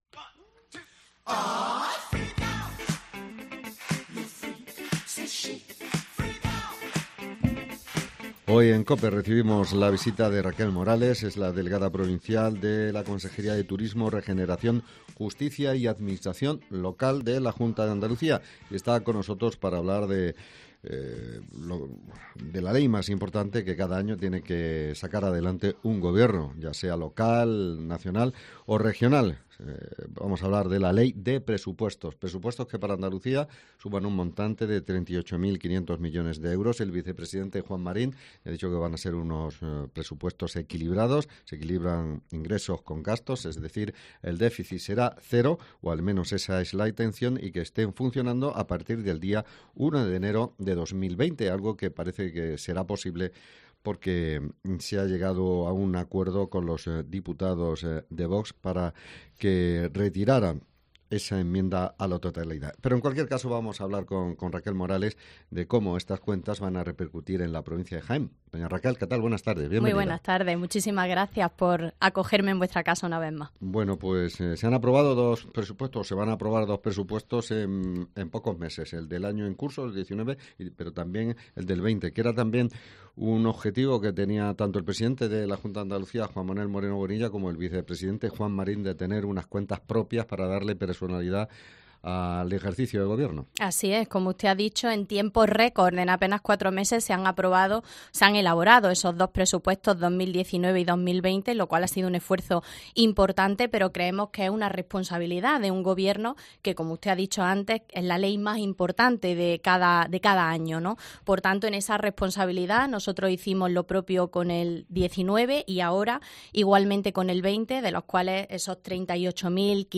Hoy ha estado en Cope la delegada provincial de la Consejería de Turismo, Regeneración, Justicia y Administración local para hablar de los...